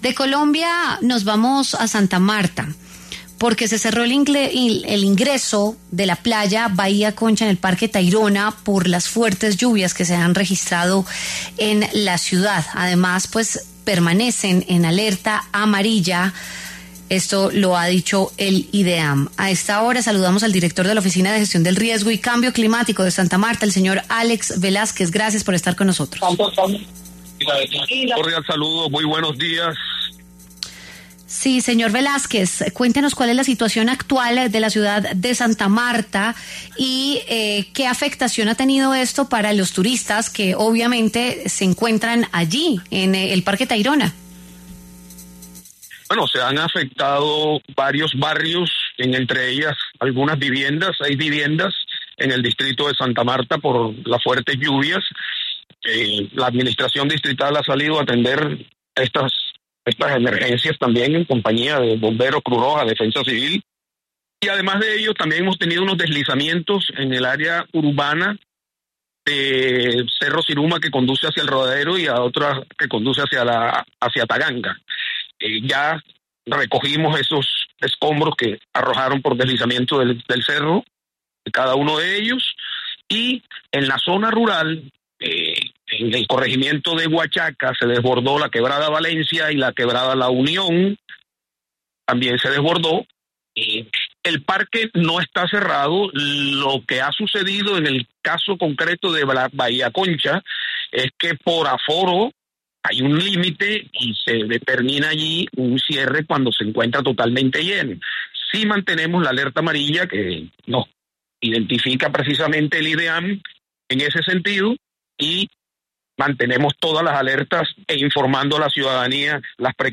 Álex Velásquez, director de la oficina de Gestión del Riesgo y Cambio Climático de Santa Marta, explicó lo sucedido.